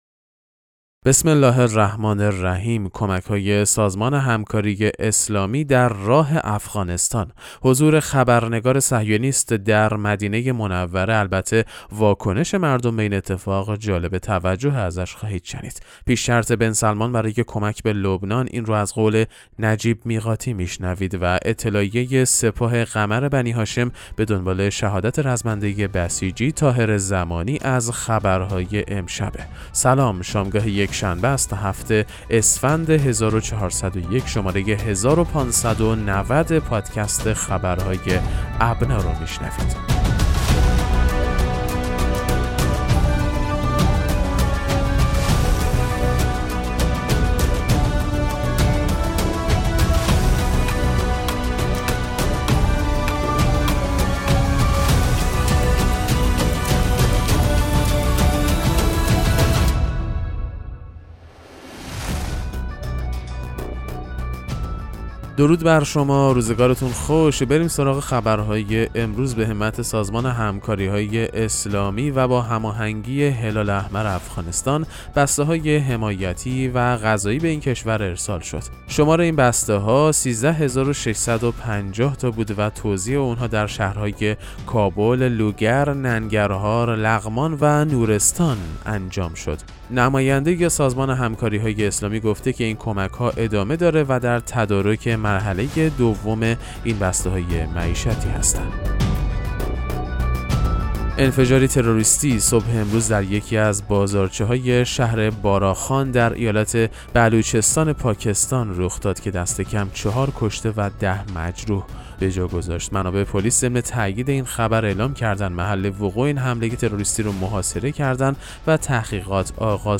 پادکست مهم‌ترین اخبار ابنا فارسی ــ 7 اسفند1401